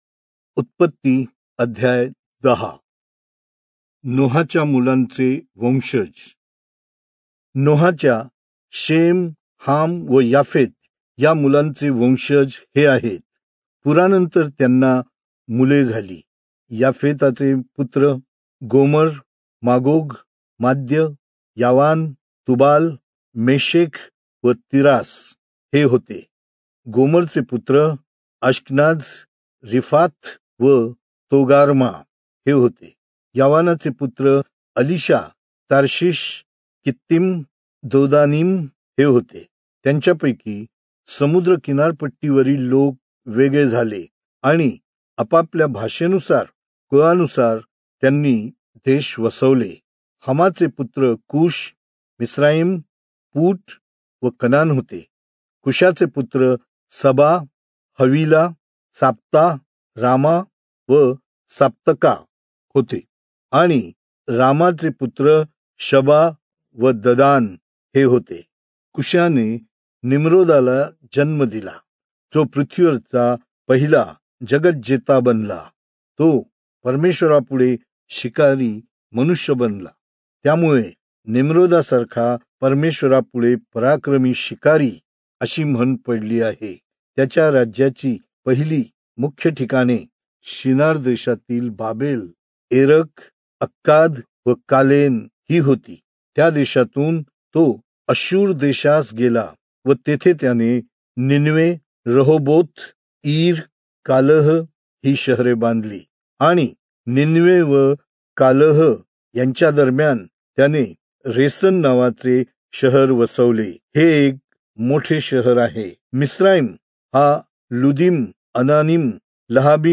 Marathi Audio Bible - Genesis 15 in Irvmr bible version